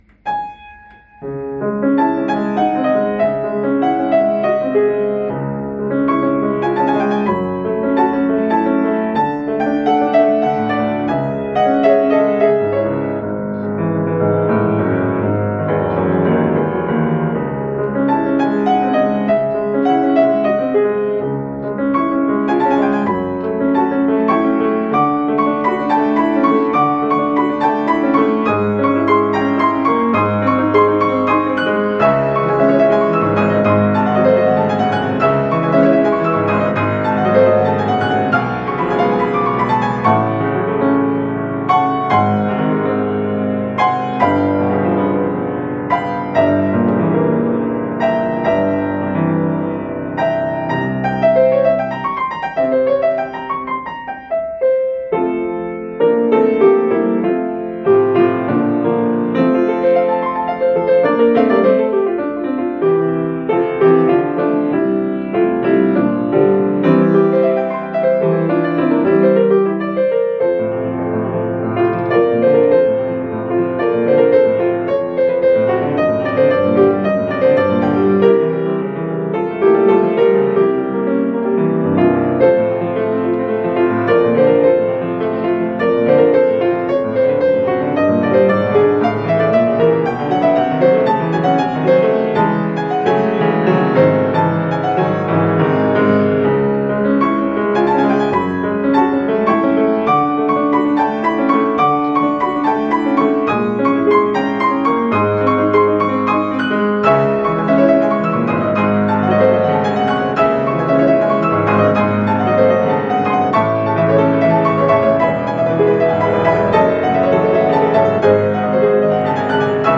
Nocturne in C-sharp minor - Piano Music, Solo Keyboard - Young Composers Music Forum
I didn't think much at the time of composing, but I did write in the style of Chopin Nocturne then.